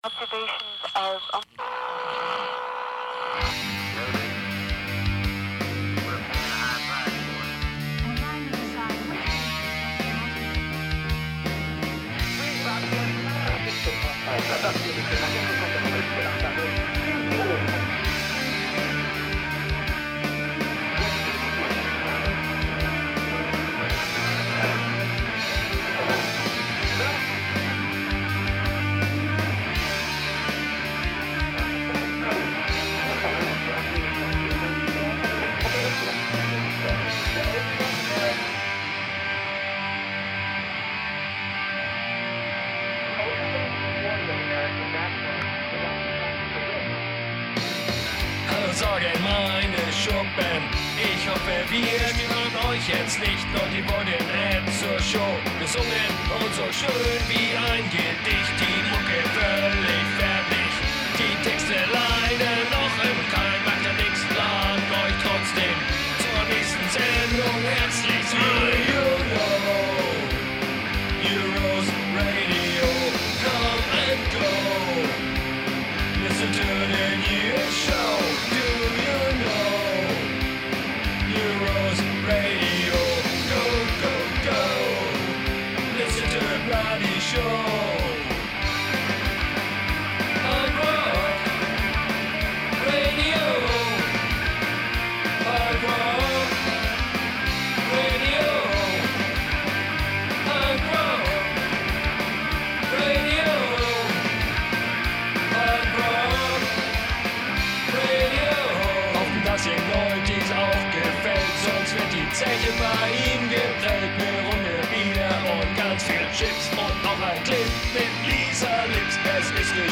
low quality web version
Lead Vocals
Doom Bass
Death Drums